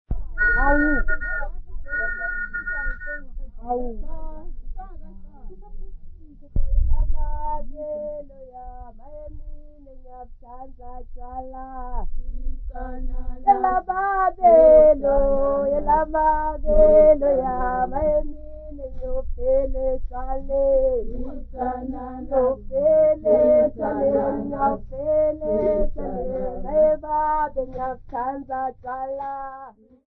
Folk music
Sacred music
Field recordings
Africa Eswatini Manzini sq
Traditional Siswati song, accompanied by the whistle and the rattle.
96000Hz 24Bit Stereo